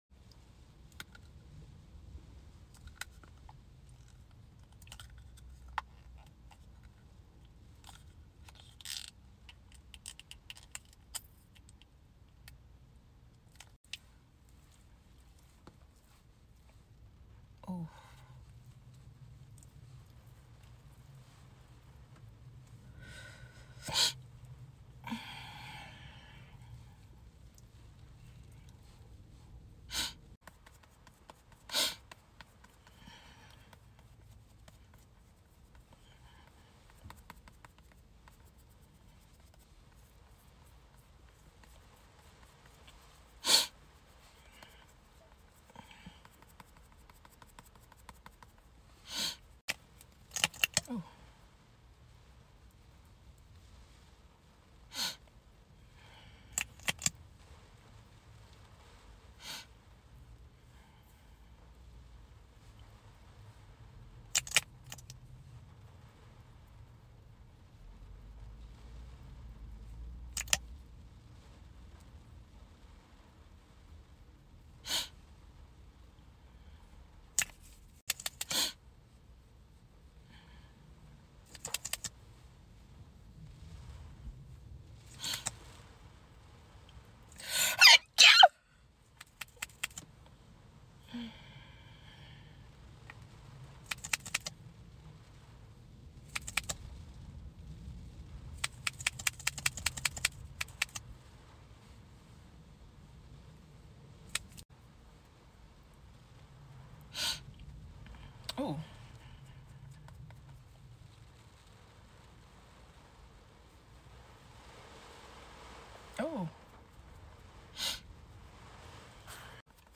sound of the rain is sound effects free download
sound of the rain is so peaceful🌧